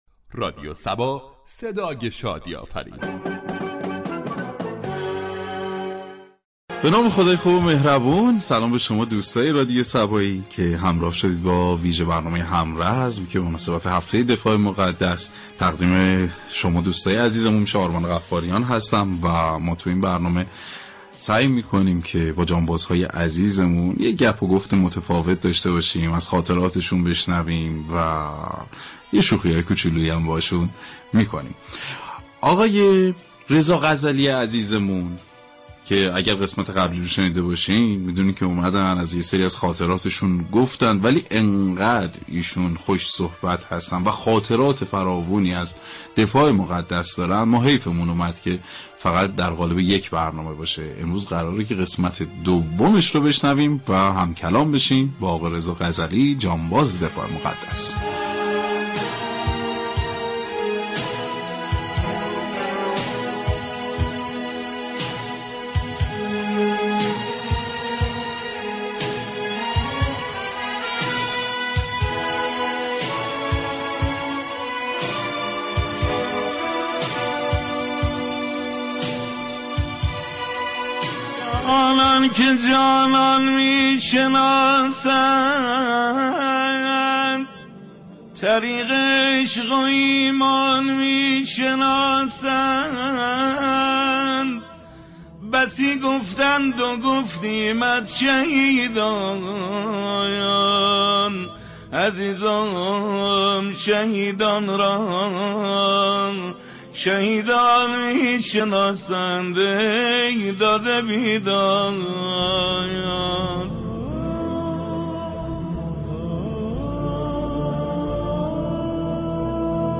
"همرزم" ویژه برنامه ای است ،با محوریت گفتگو با یكی از جانبازان یا ایثارگرانی كه فرصت حضور در جبهه‌های جنگ را داشته اند.